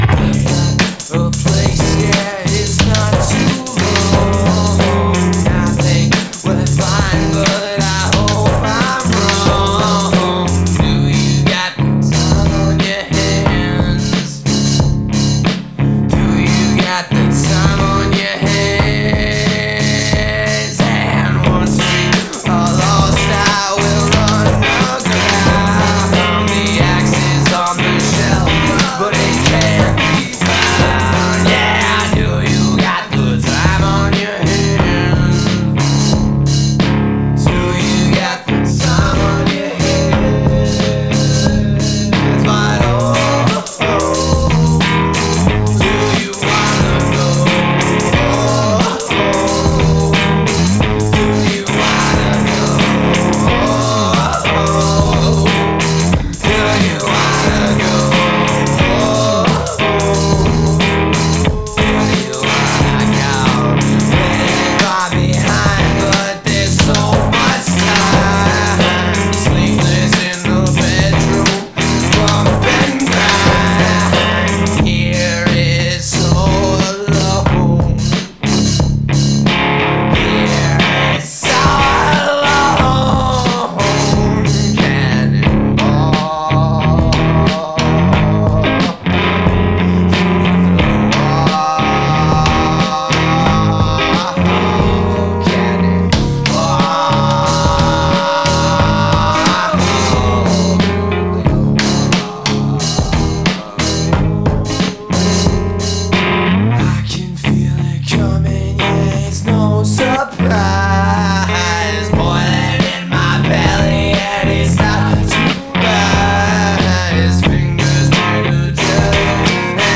pop folk punk lo fi foutraque